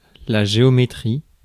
Ääntäminen
Ääntäminen France: IPA: [la ʒeɔmetʁi] Tuntematon aksentti: IPA: /ʒe.ɔ.me.tʁi/ Haettu sana löytyi näillä lähdekielillä: ranska Käännös Substantiivit 1. geomeetria Suku: f .